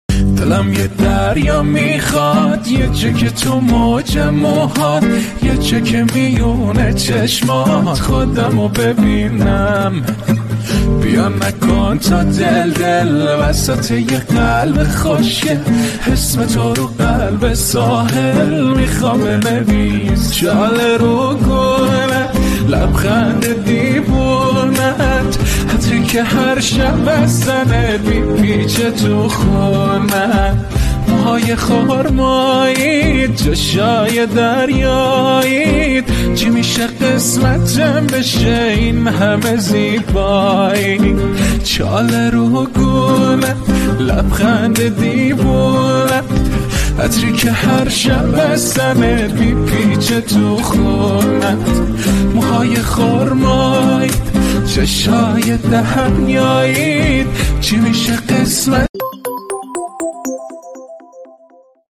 با گیتار